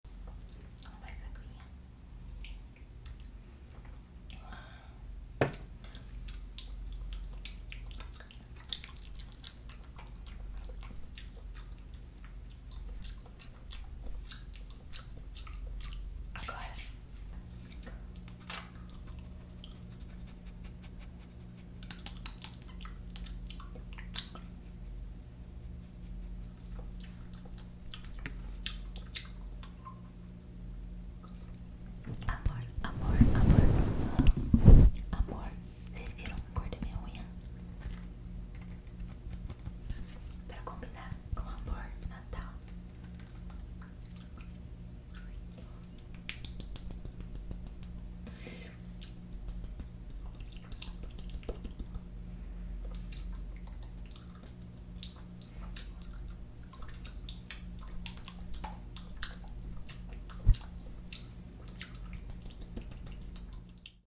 Asmr | Spit Painting 🎥